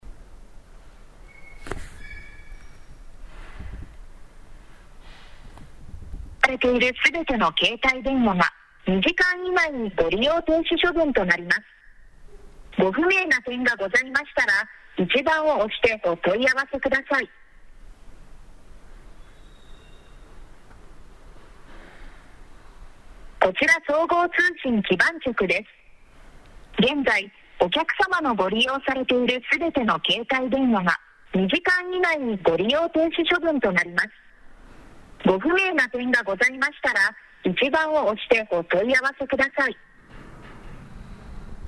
令和6年7月にかかってきた総務省（総合通信基盤局）を装う自動音声はこちらです。